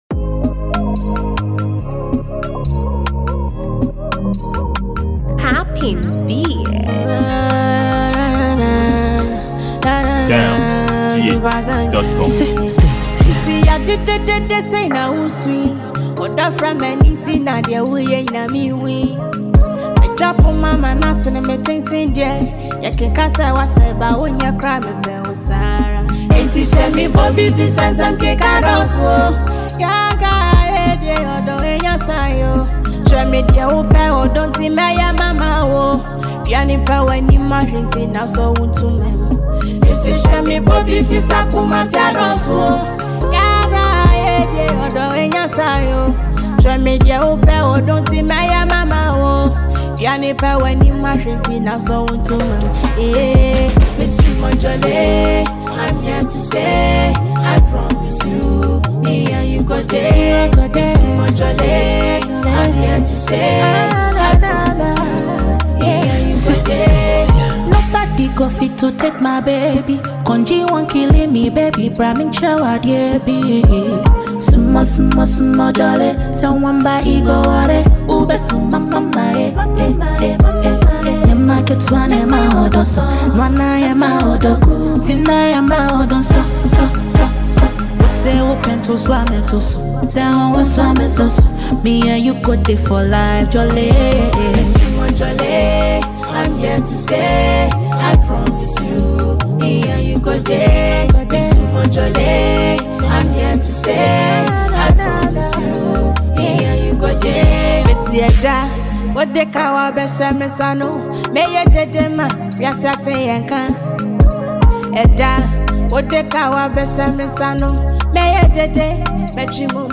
Genre: Afrobeats
Fast-rising Ghanaian female singer